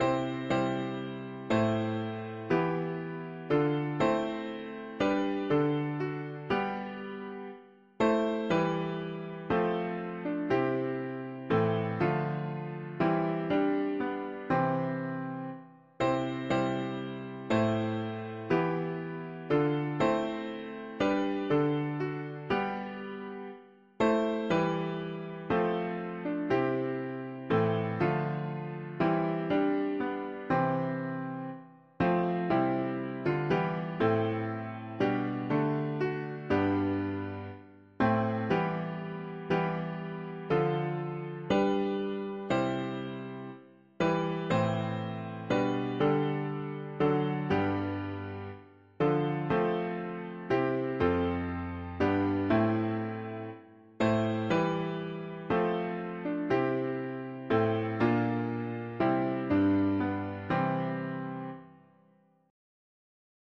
Our helper he amid the flood of mortal ills prevailing, for still our ancient foe doth seek to… english christian 4part
Key: C major